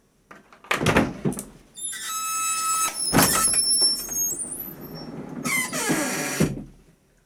Bajar un mueble cama
abrir bajar bisagra cama chasquido chirriar chirrido mueble rechinar ruido sonido
Sonidos: Acciones humanas Sonidos: Hogar